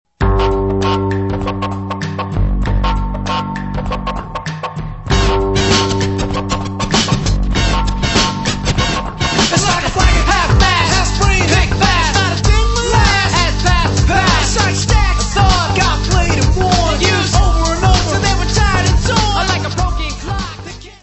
Área:  Pop / Rock